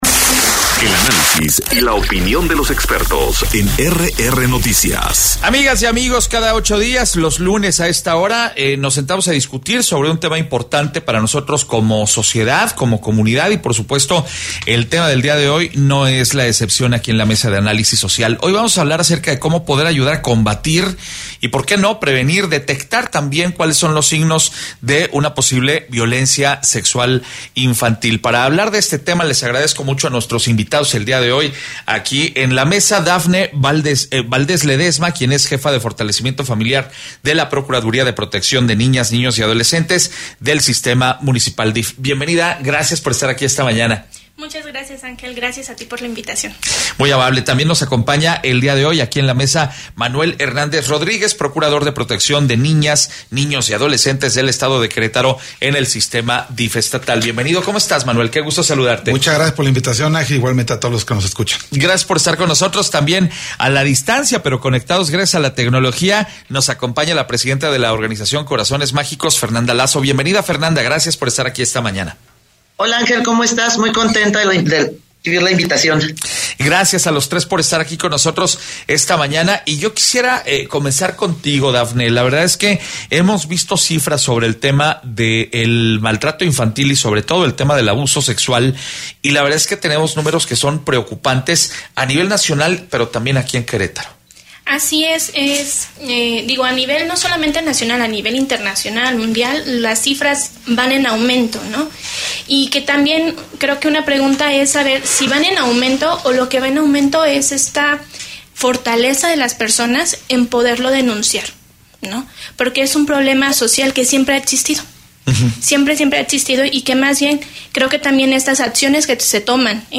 Relevo en el INE, ¿qué sigue ahora?: Mesa de Análisis - RR Noticias